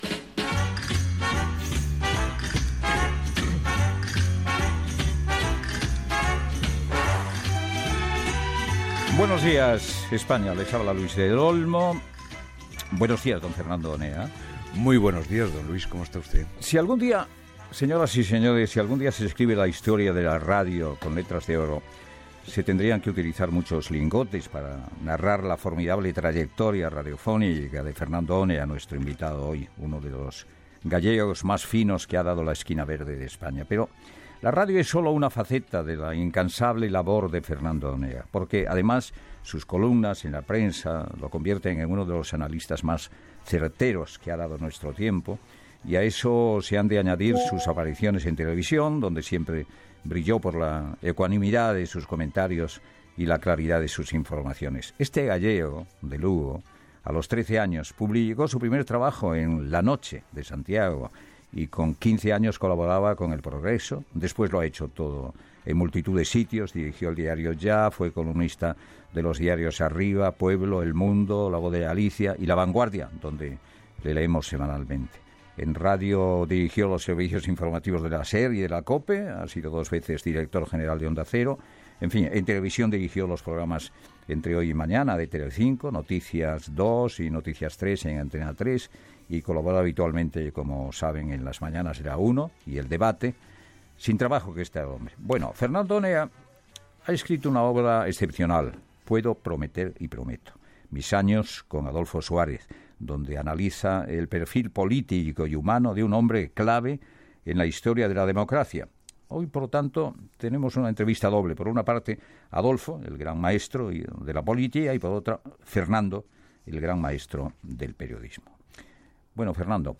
Salutació i entrevista al periodista Fernando Ónega